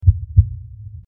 Heartbeat
Heartbeat.mp3